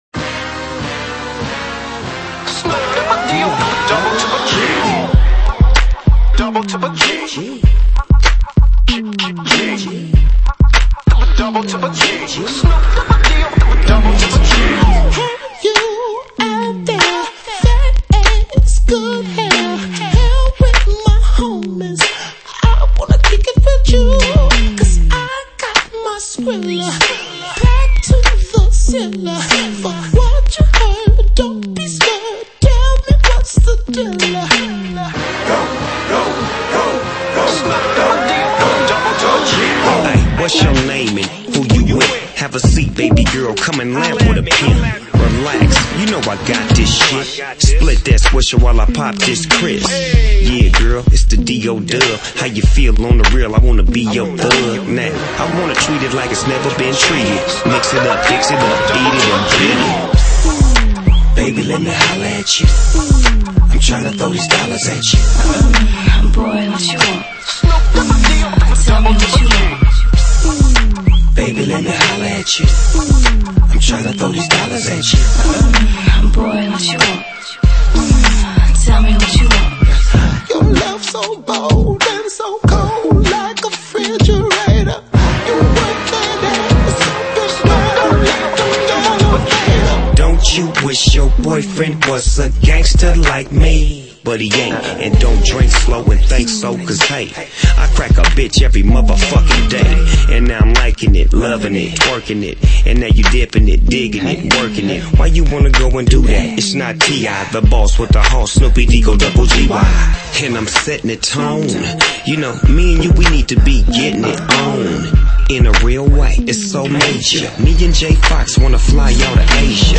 Rap/Hip Hop [49]